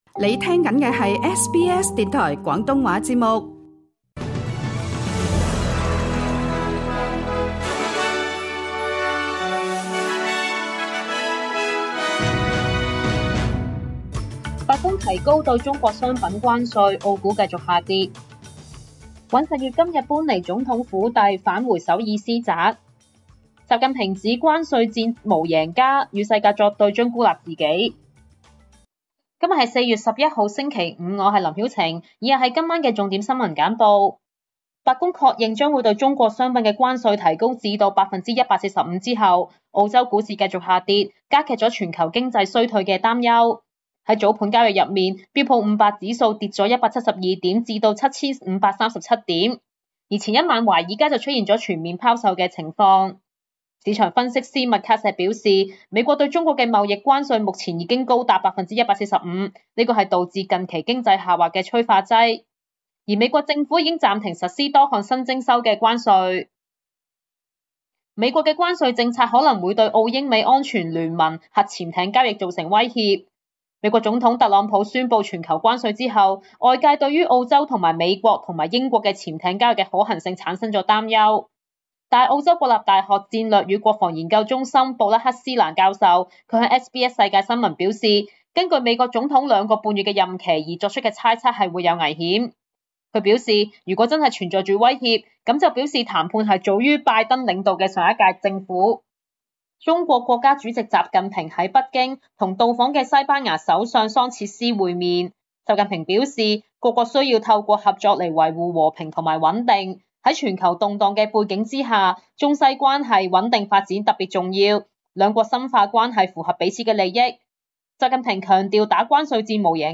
SBS晚間新聞（2025年4月11日）
請收聽本台為大家準備的每日重點新聞簡報。